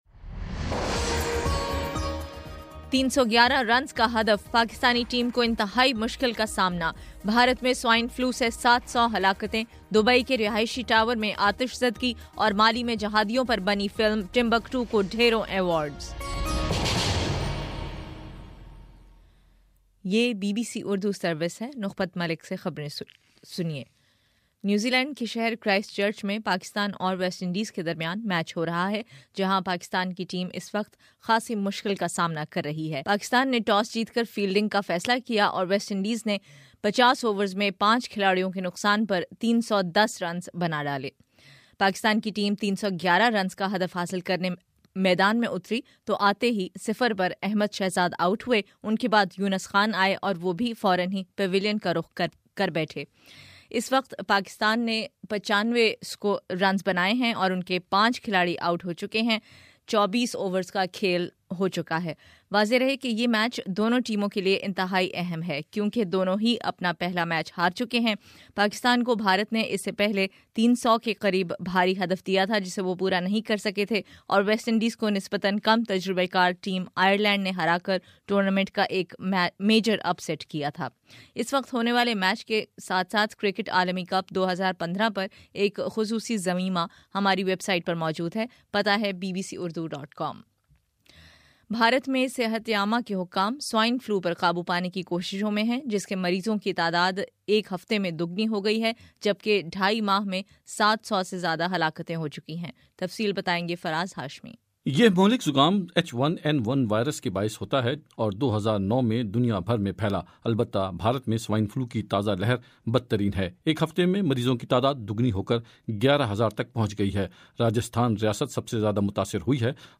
فروری 21 : صبح نو بجے کا نیوز بُلیٹن